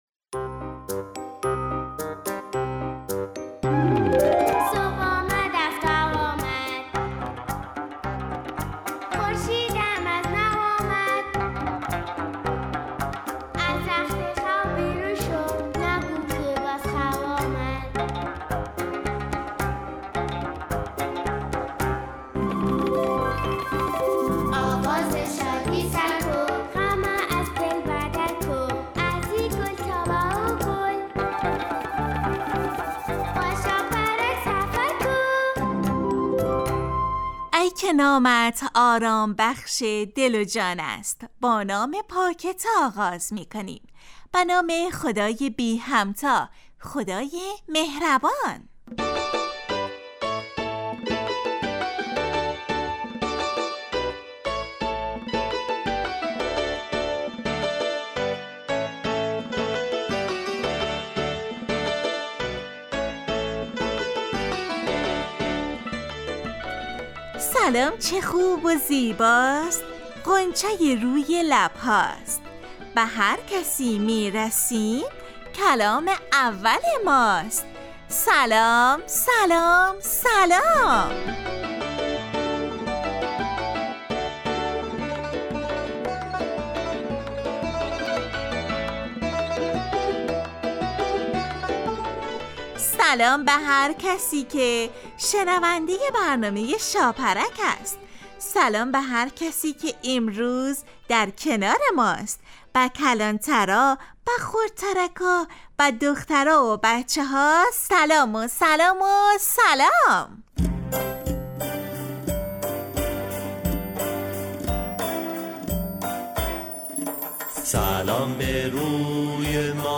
شاپرک برنامه ای ترکیبی نمایشی است که برای کودکان تهیه و آماده میشود.این برنامه هرروز به مدت 15 دقیقه با یک موضوع مناسب کودکان در ساعت 8:15 صبح به وقت افغانستان از رادیو دری پخش می گردد.